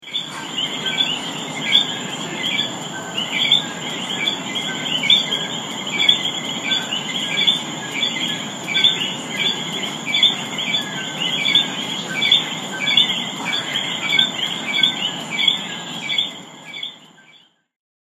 The coquí is a tiny tree frog with a loud, high pitch sound that can be heard from far away.
I didn’t get a picture of coquíes in the forest, but we hear them at the house everyday from the patio. Click play on the audio clip below to hear the unique sound of the coquí.
coquies-puerto-rico-audio.mp3